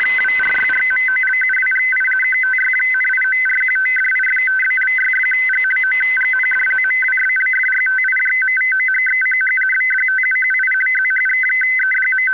97 Кб 23.01.2008 02:43 FSK-2 500 Hz 50 Bd ACF=51 biv_2